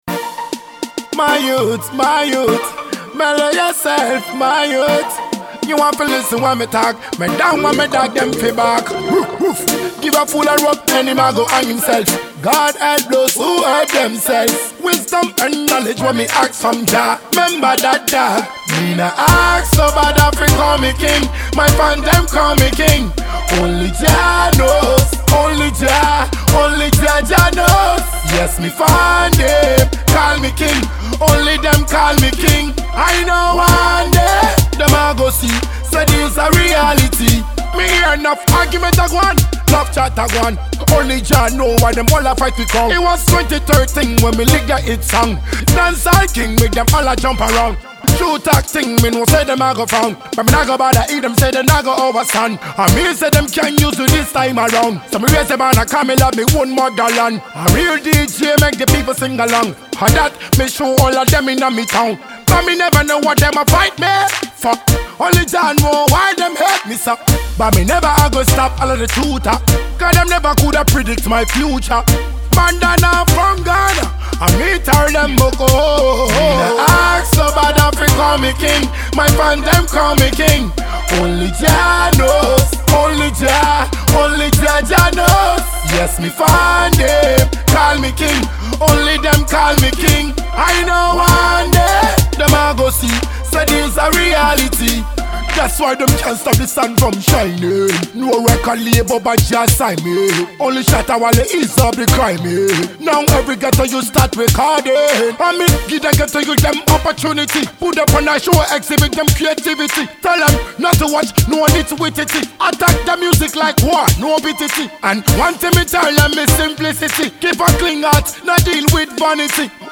Its a straight dancehall banger